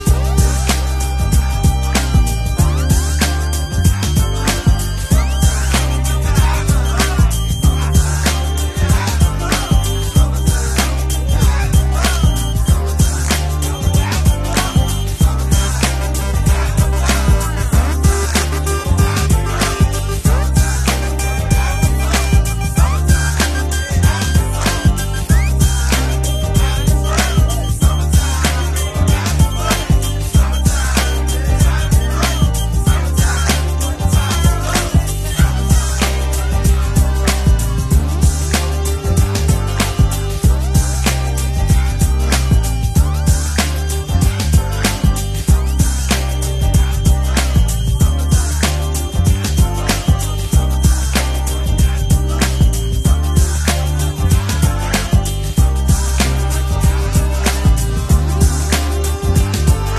The 54 Corvette on display sound effects free download
The 54 Corvette on display at the Yorkshire Elegance at Grantley Hall